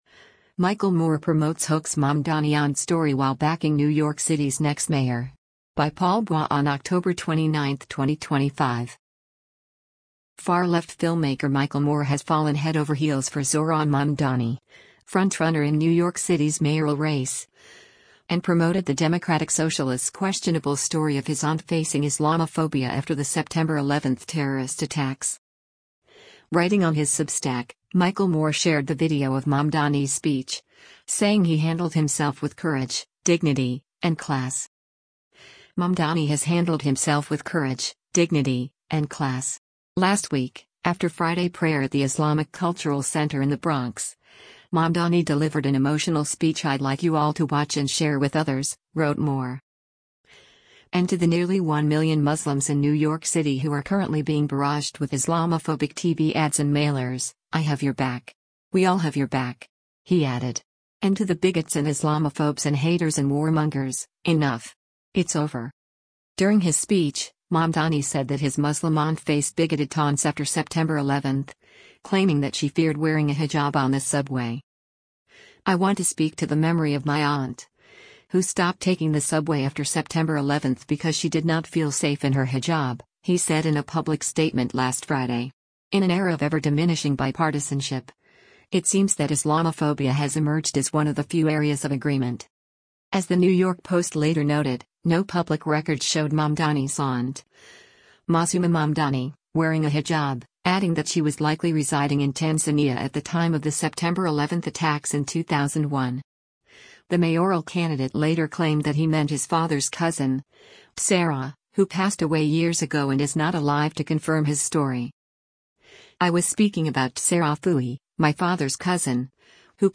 “I want to speak to the memory of my aunt, who stopped taking the subway after September 11th because she did not feel safe in her hijab,” he said in a public statement last Friday.